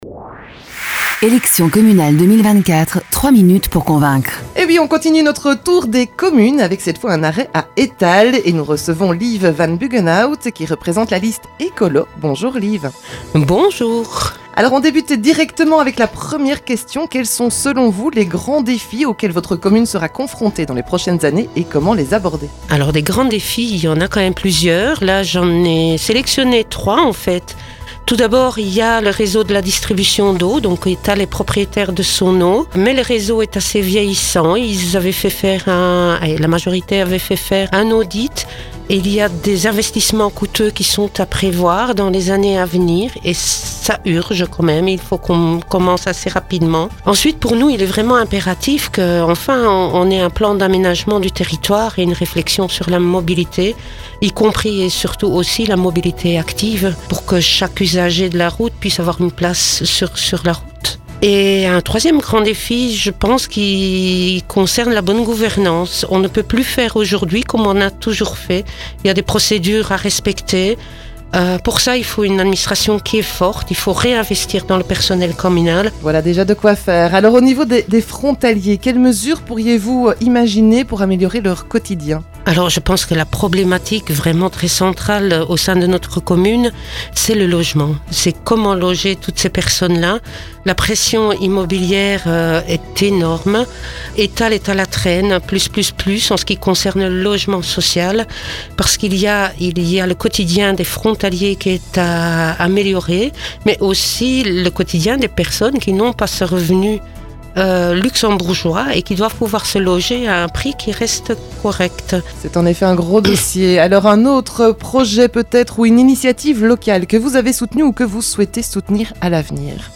Retour sur les élections communales avec un arrêt à Etalle où nous avons rencontré les têtes de liste qui détaillent leur programme dans nos studios.